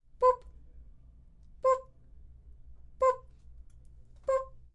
主动雷达的声音